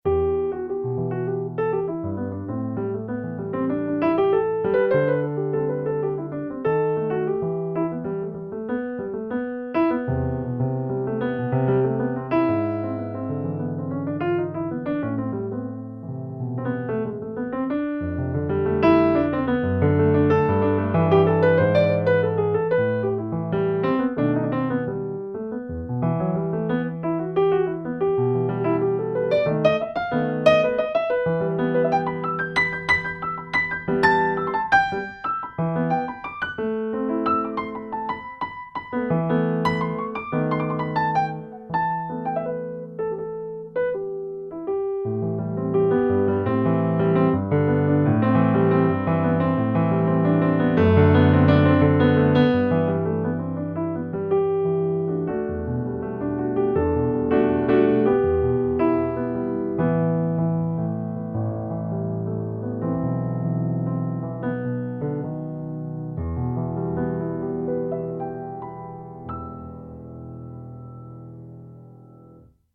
A busy but sad improv. Lots of movement in classic cadenzas in a minor key.
Eine unruhige aber traurige Impro.